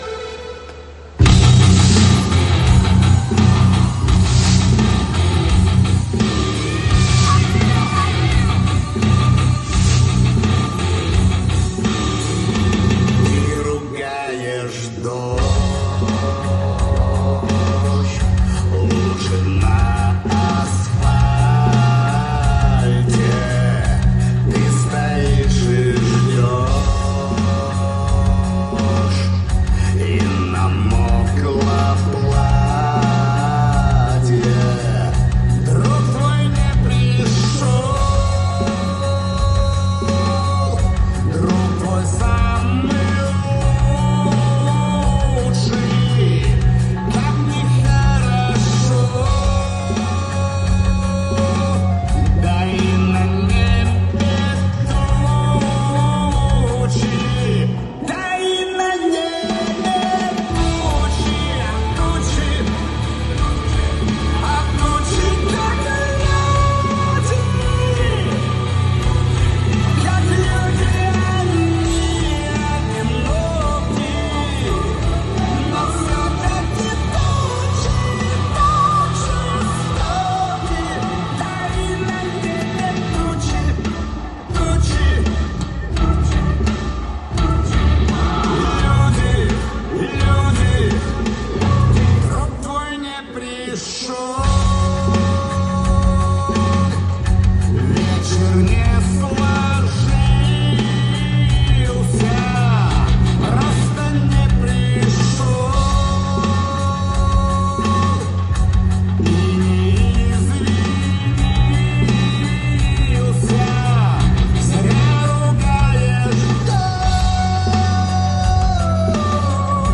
Кавер 2025